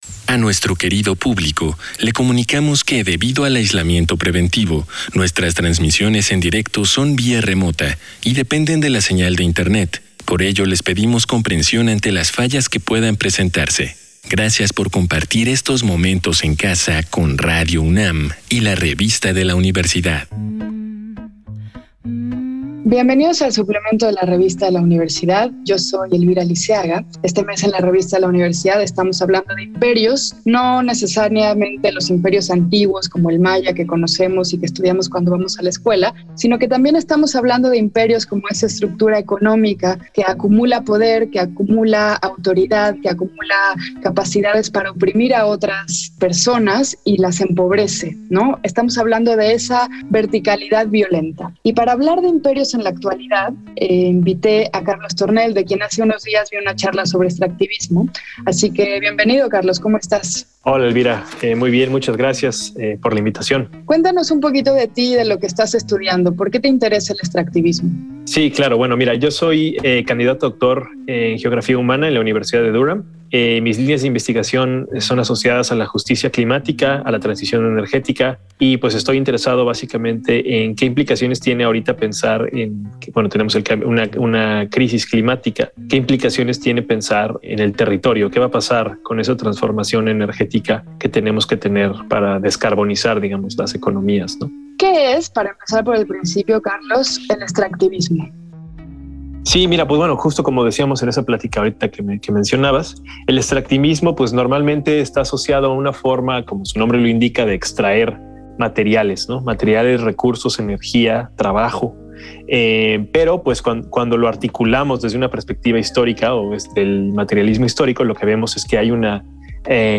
Fue transmitido el jueves 18 de noviembre de 2021 por el 96.1 FM.